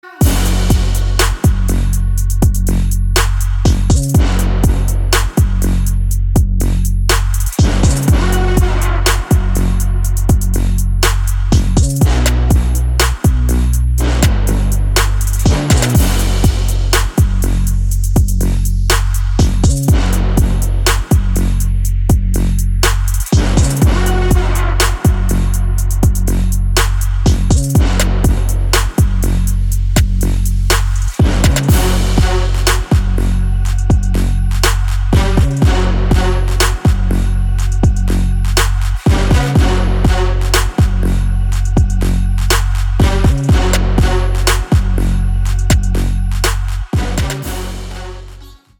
громкие
мощные
Electronic
без слов
качающие
Стиль: trap